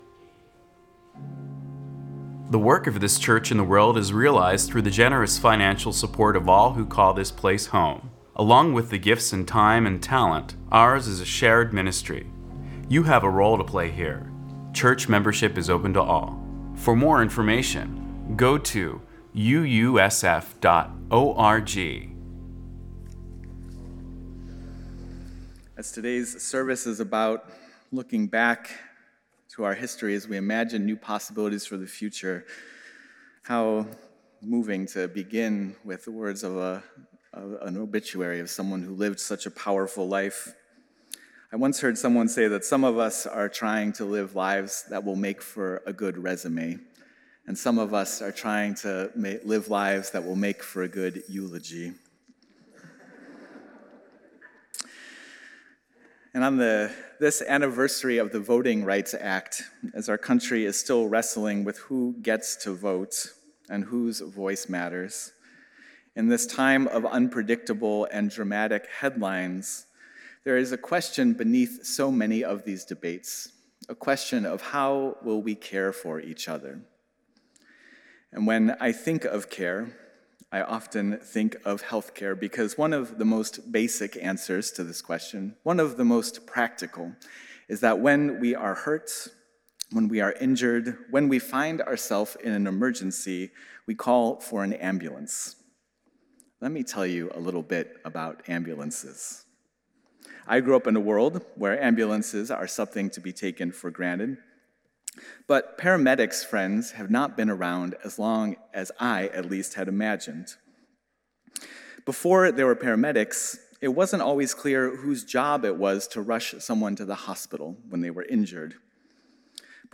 It's not so much that we loosen the definition but that we see so clearly how it takes so many different skills and gifts to make the whole of what we want to do together possible. This Sunday we will ask three leaders, in different tenures of leadership, to reflect on what calls them into the work and what they have learned and reflect on our own notion of and call to lead.